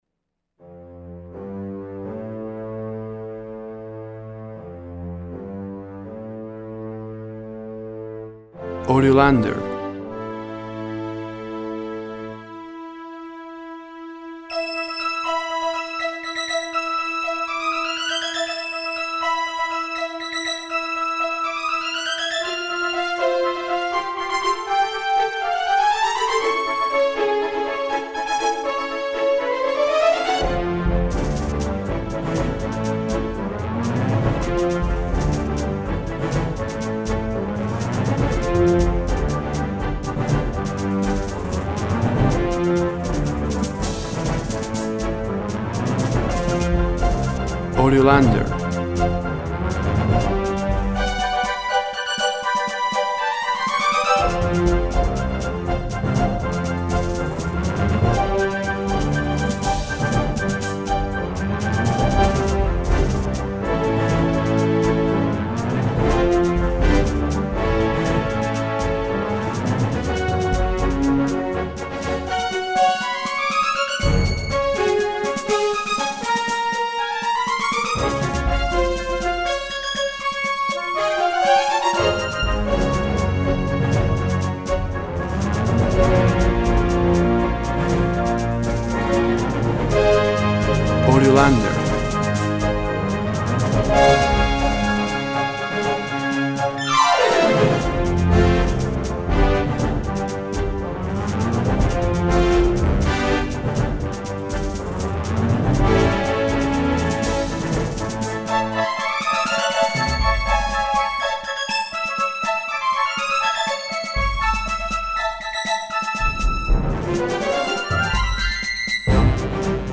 Virtual instruments section brass, epic sound action.